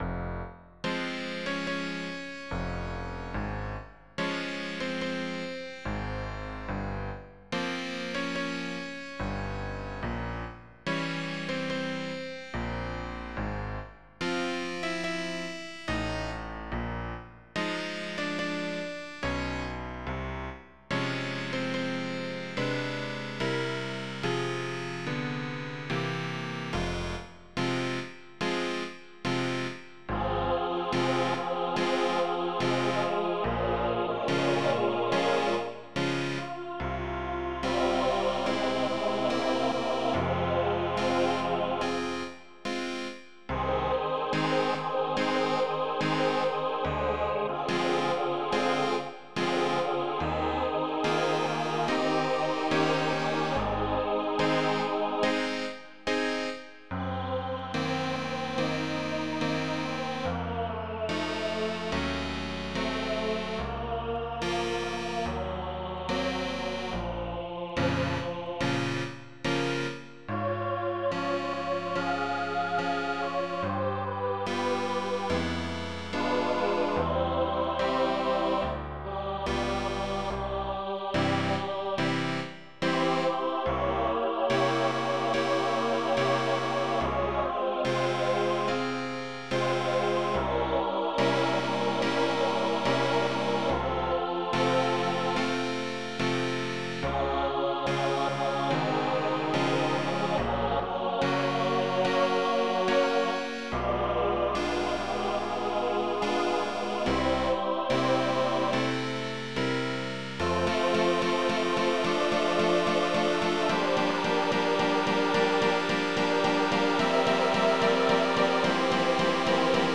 This is an Easter anthem for SATB choir and piano arranged from a song by Charles Gounod with words by Cecil Frances Alexander.
A 2 page voice parts score and a computer generated sound file are also included.